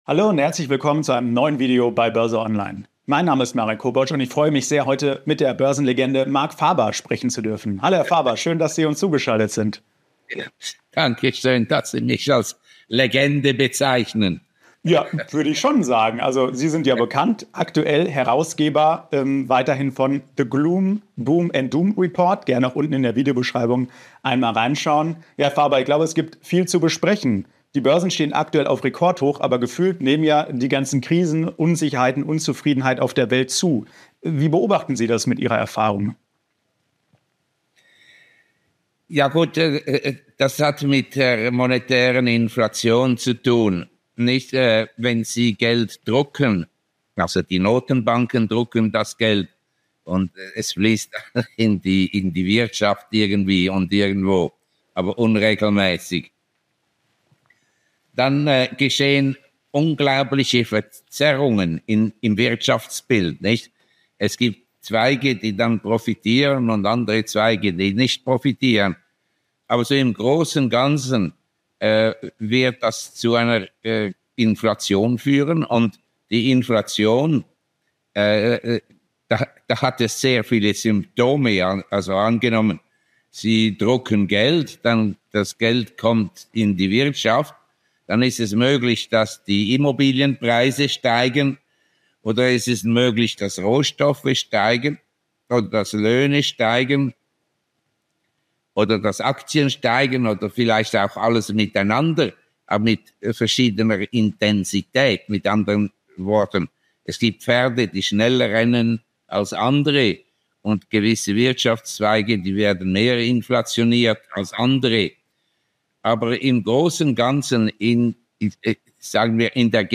Verarmen jetzt immer mehr Menschen? BÖRSE ONLINE im Talk mit Schweizer Ökonom Marc Faber ~ BÖRSE ONLINE Podcast
Denn die Sozialisten hätten den Staat übernommen und würden das Volk immer ärmer machen. Was die Hintergründe dafür sind, wie sich jeder dagegen wehren kann und was zum kompletten Kollaps unserer Gesellschaft führen kann, das verrät Marc Faber jetzt im neuen Interview bei BÖRSE ONLINE.